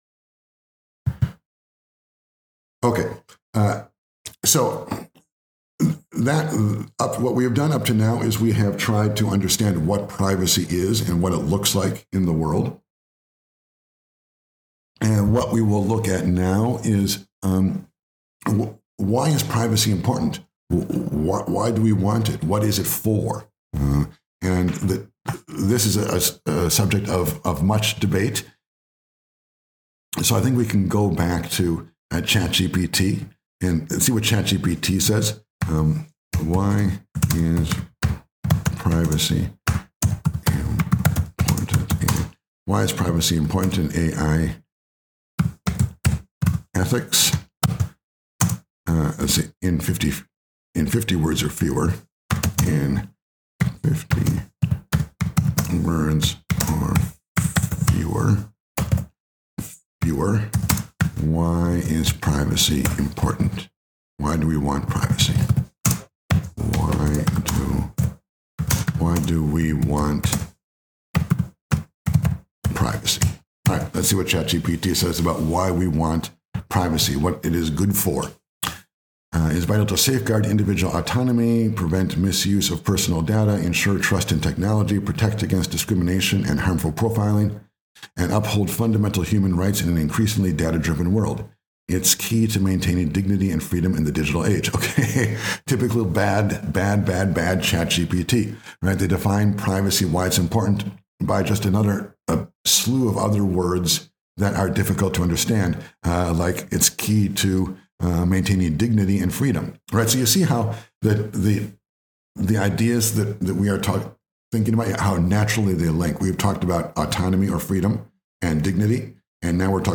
March 9 → 13 Lecture In this lecture we explore part 2 of the AI ethics of Privacy.
The lecture is meant to include images, but there is a pure audio version here that you may download.